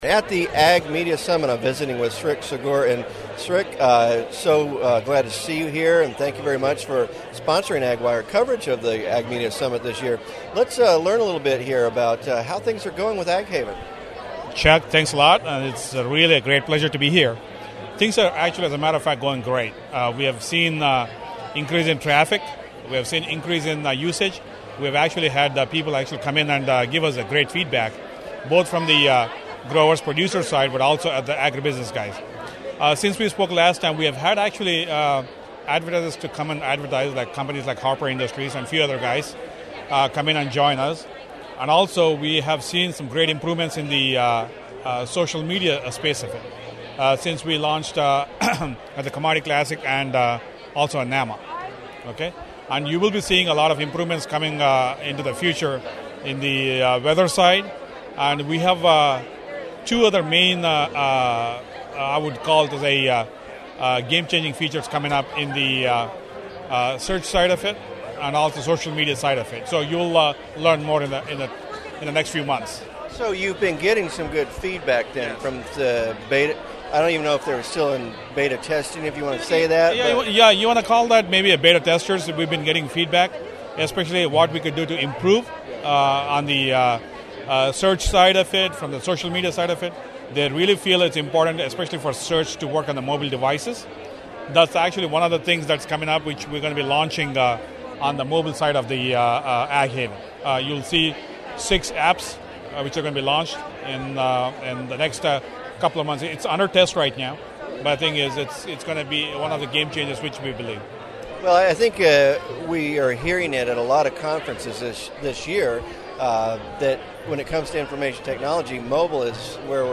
AgWired coverage of the Ag Media Summit is sponsored by BASF and AgHaven